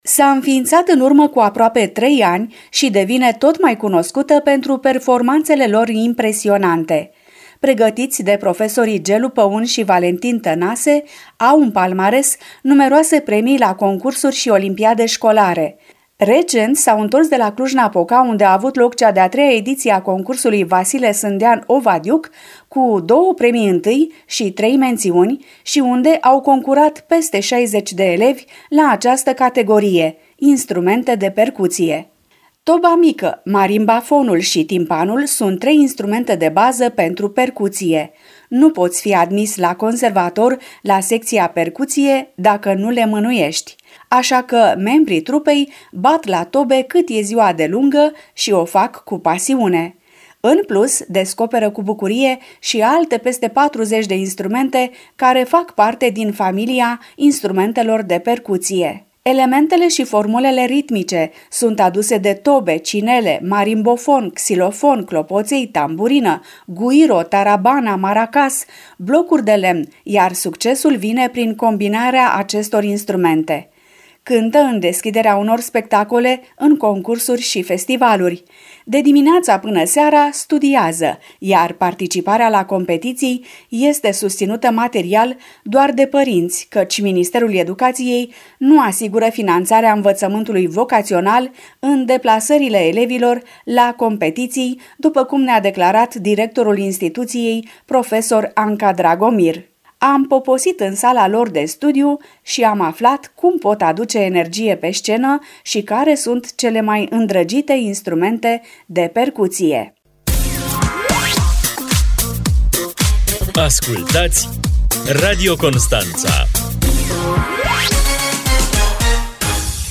Toba mică, marimbafonul și timpanul sunt trei instrumente de bază pentru percuție.
Elementele și formulele ritmice sunt aduse de tobe, cinele, marimbofon, xilofon, clopoței, tamburină, guiro, tarabana, maracas, blocuri de lemn, iar succesul vine prin combinarea acestor instrumente.